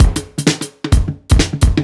OTG_Kit 5_HeavySwing_130-A.wav